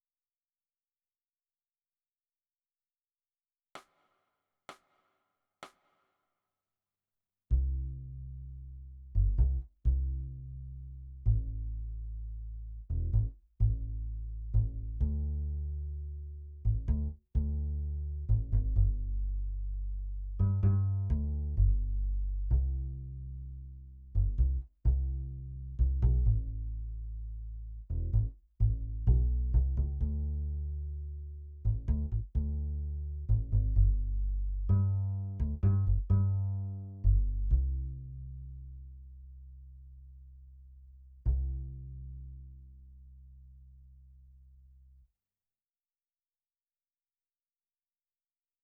※曲の中には、無音部分が入っていることもあります。